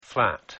پیش از اینکه وارد مبحث اصلی شویم نگاهی داشته باشید به تلفظ های این دو کلمه: